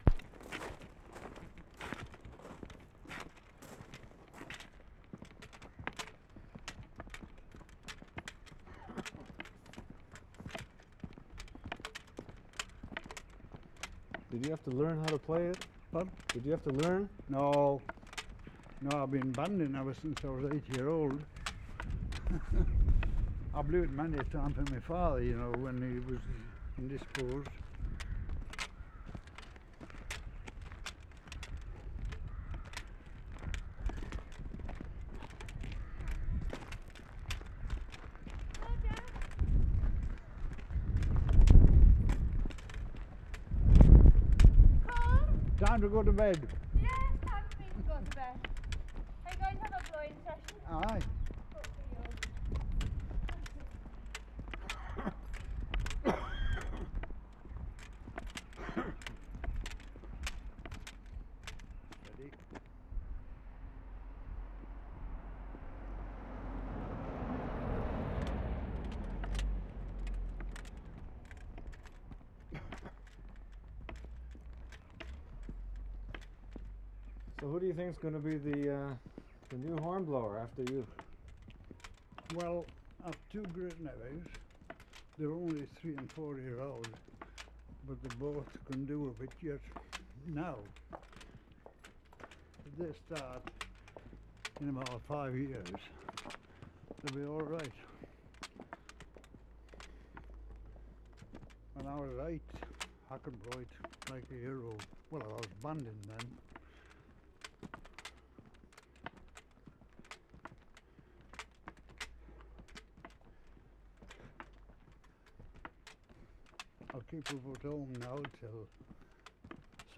Wensleydale, Yorkshire May 7/75
The clattering sound is made by the chain on the horn, clanking against it. This is a South African buffalo horn.